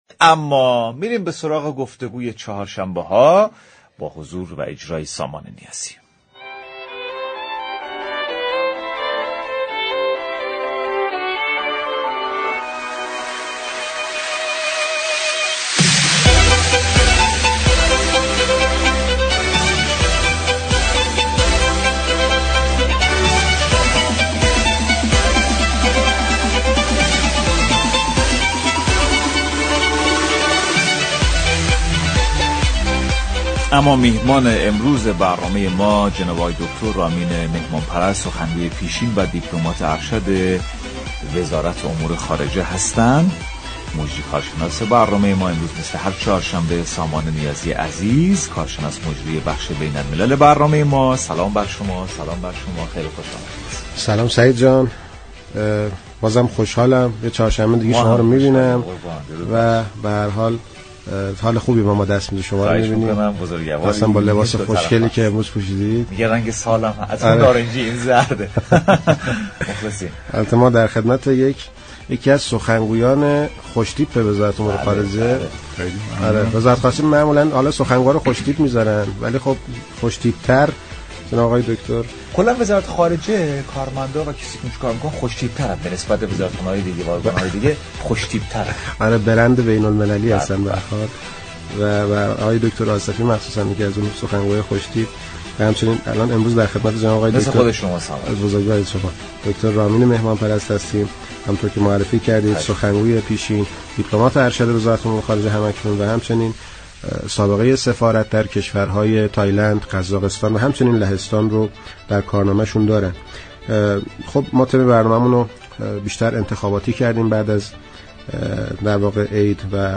به گزارش پایگاه اطلاع رسانی رادیو تهران، رامین مهمانپرست سخنگوی پیشین و دیپلمات ارشد وزارت امور خارجه كشورمان با اشاره به انتخابات به عنوان نمود دموكراسی كشور و نقش آن در موفقیت دولتی مقتدر در عرصه سیاست خارجی به برنامه سعادت آباد رادیو تهران گفت: اقتدار هر كشوری به عوامل مختلفی از جمله انسجام و وحدت ملی و حمایت مردم از نظام بستگی دارد.